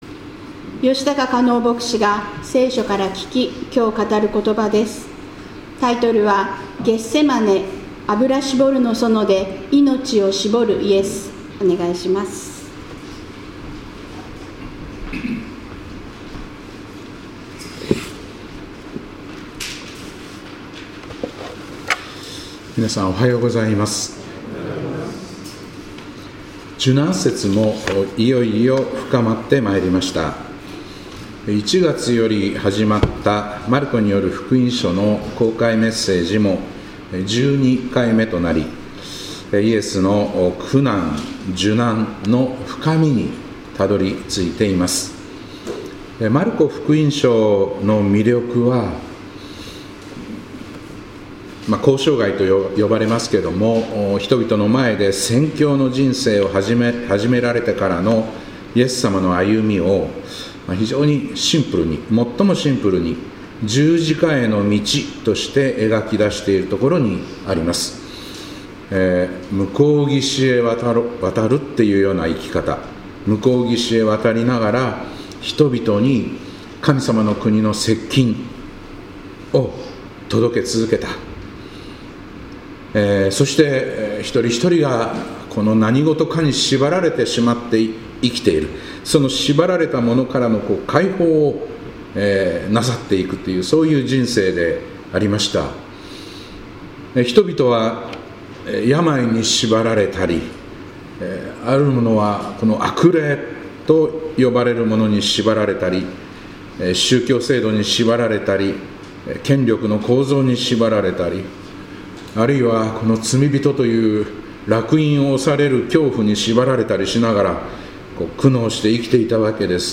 2026年3月22日礼拝「ゲッセマネ（油搾りの園）で命を搾るイエス」